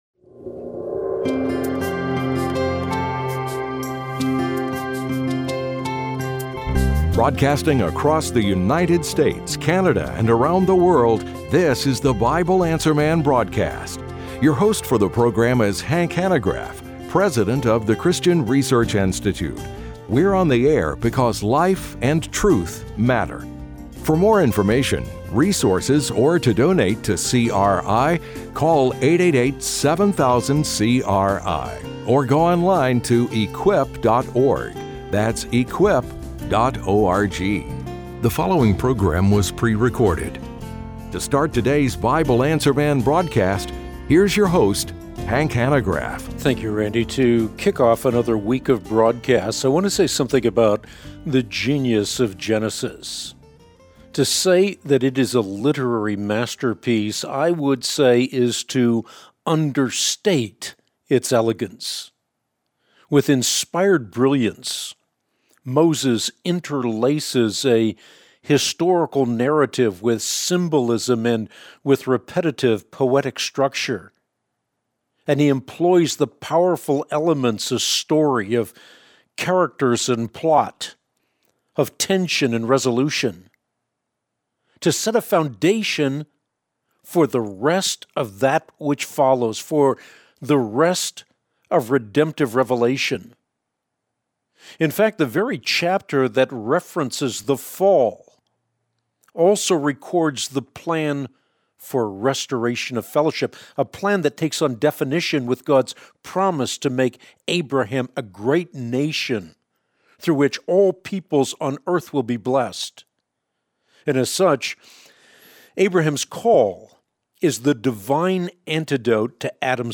On today’s Bible Answer Man broadcast (05/27/25), Hank shares on the genius of the Book of Genesis and how it is an understatement to call it a literary masterpiece.
Hank also answers the following questions: